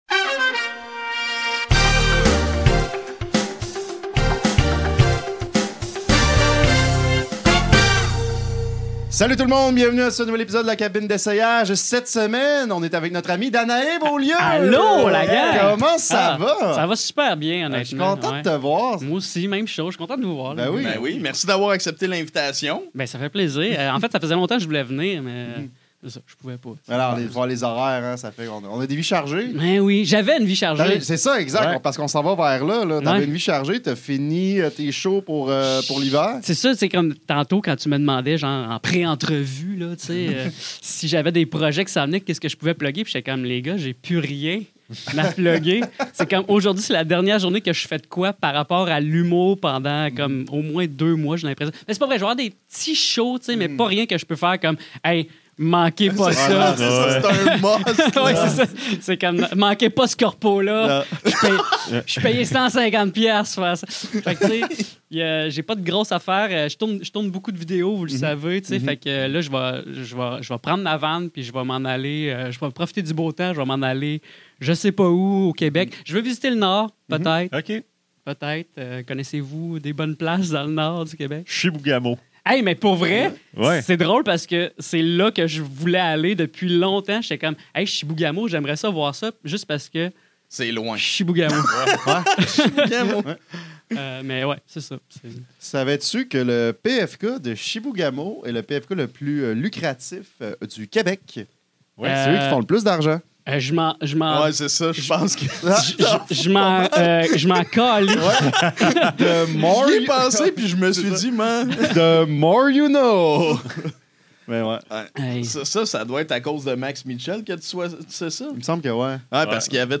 La Cabine d’Essayage est un podcast qui met l’emphase sur la création et l’improvisation. À chaque épisode, Les Piles-Poils et un artiste invité doivent présenter un court numéro sous forme de personnage (ou de stand-up) à partir d’un thème pigé au hasard.